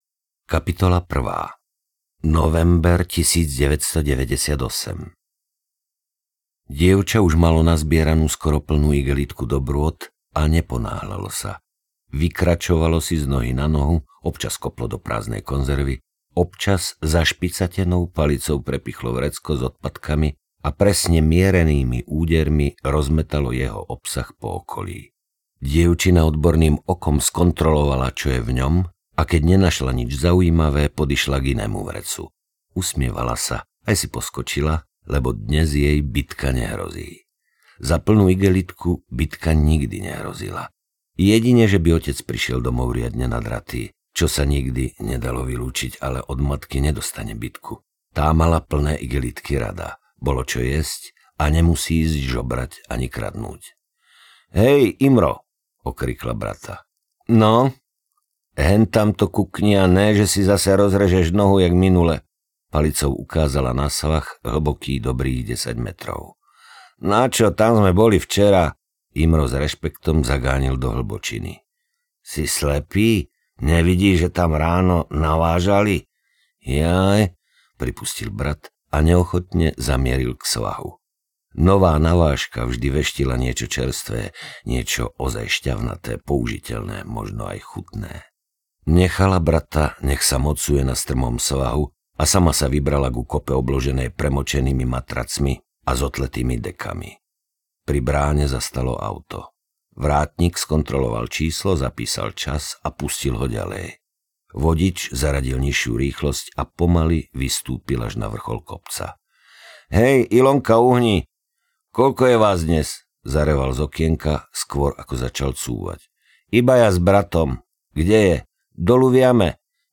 Jednou nohou v hrobe audiokniha
Ukázka z knihy
• InterpretMarián Geišberg